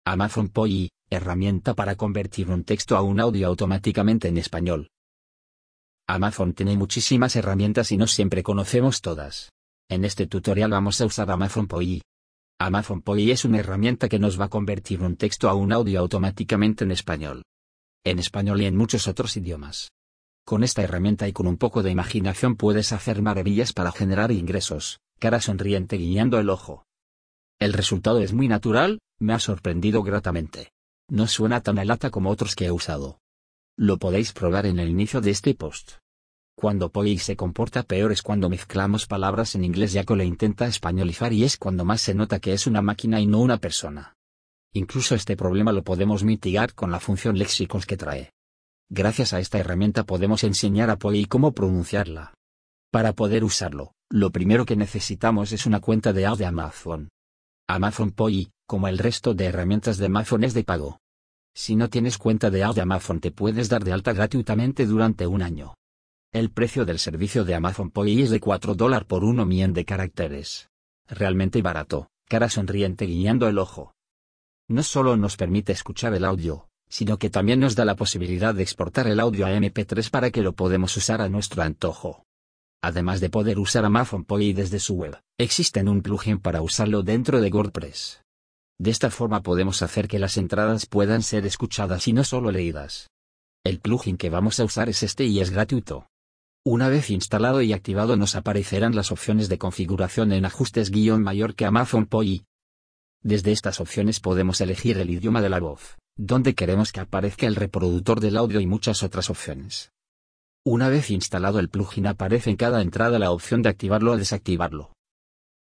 Amazon Polly es una herramienta que nos va a convertir un texto a un audio automáticamente en Español.
El resultado es muy natural, me ha sorprendido gratamente. No suena tan a «lata» como otros que he usado.
Cuando Polly se comporta peor es cuando mezclamos palabras en Inglés ya que lo intenta «Españolizar» y es cuando más se nota que es una máquina y no una persona.